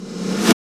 SNR REVRS 00.wav